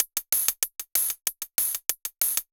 Index of /musicradar/ultimate-hihat-samples/95bpm
UHH_ElectroHatC_95-05.wav